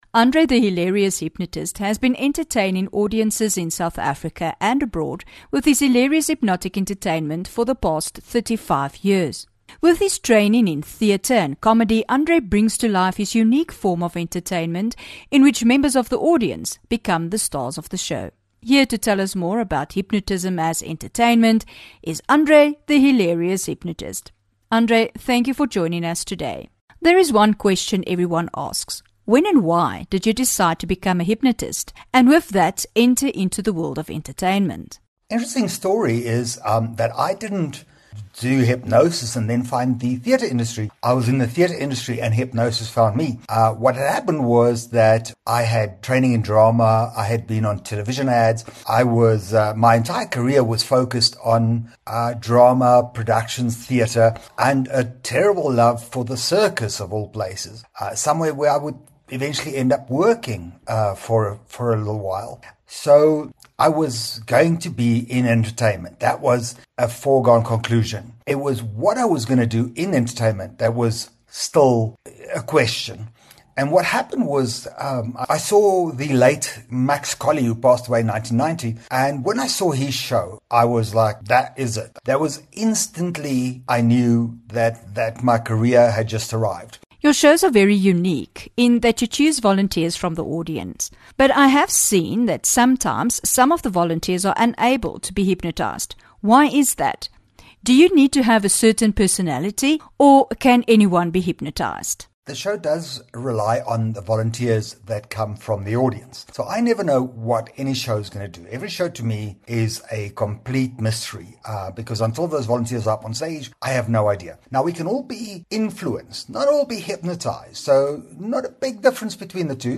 15 Jan INTERVIEW